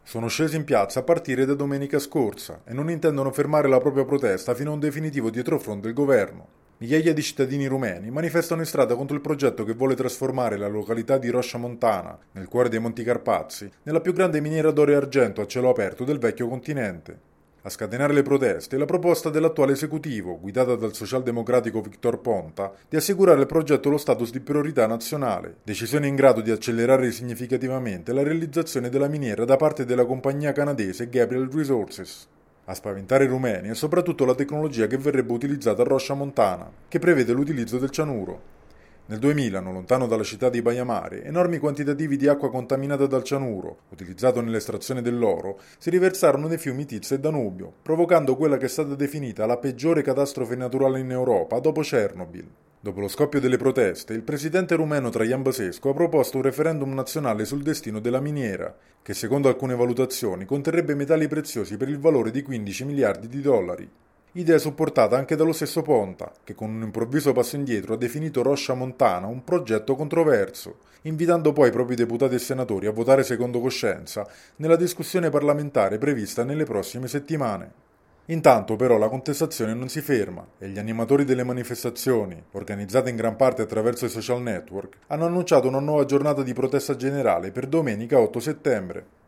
Anche in Romania migliaia di cittadini scendono in piazza. Al centro delle proteste, il contestato progetto di una compagnia canadese di creare in località Rosia Montana la più grande miniera d’oro a cielo aperto in Europa, utilizzando cianuro per l’estrazione del metallo prezioso. Il servizio